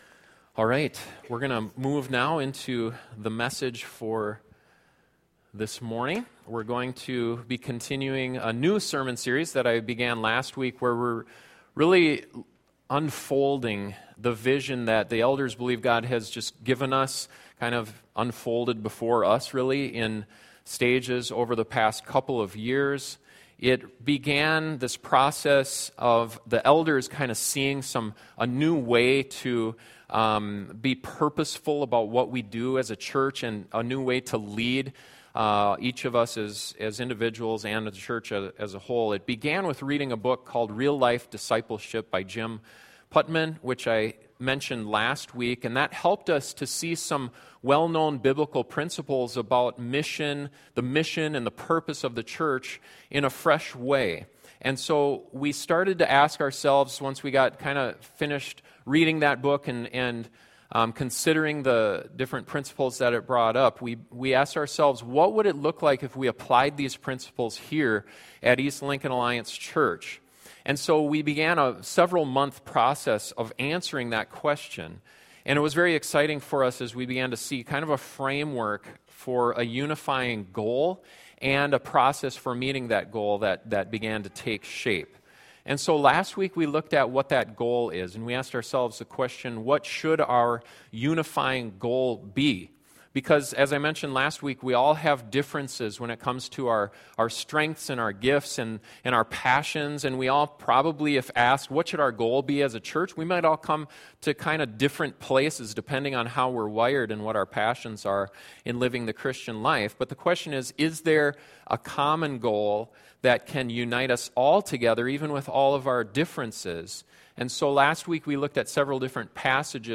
Knowing a unifying goal is vitally important for a church, but knowing a clear process for pursuing that goal is equally as important. This sermon is the second in the series: Vision for East Lincoln and looks at something that we often forget about when it comes to our spiritual growth.